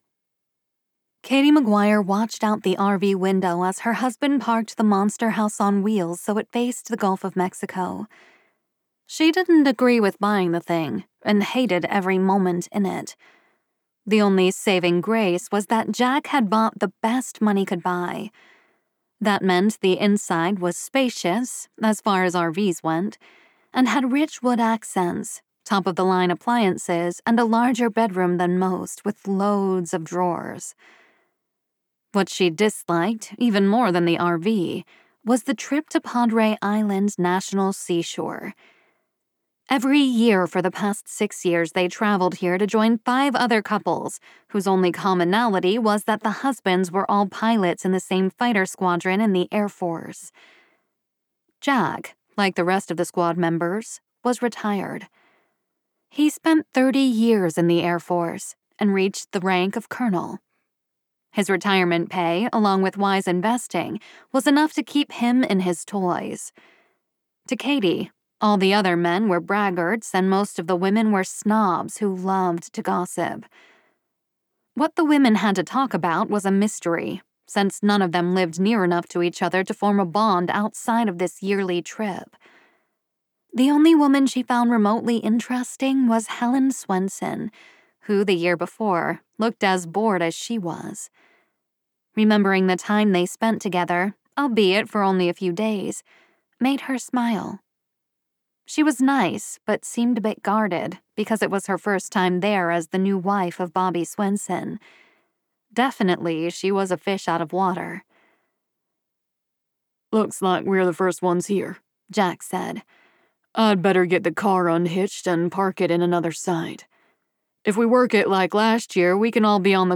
If I Were a Boy by Erin O’Reilly [Audiobook]